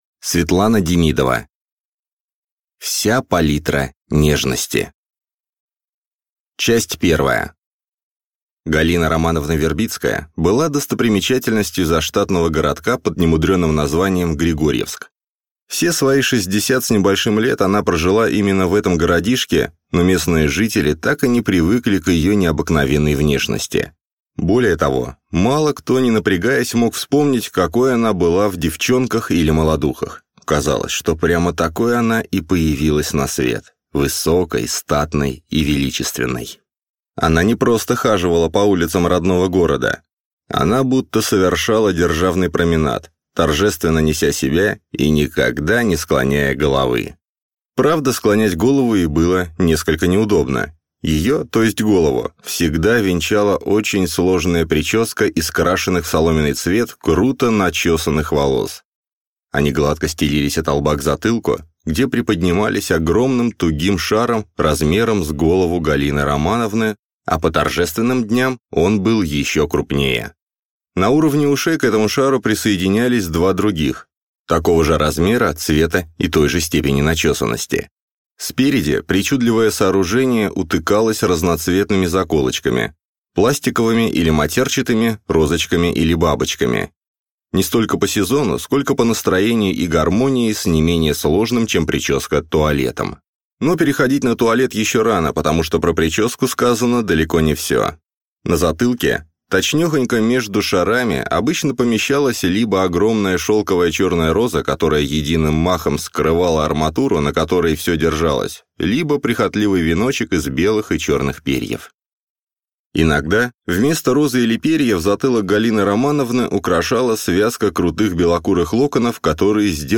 Аудиокнига Вся палитра нежности | Библиотека аудиокниг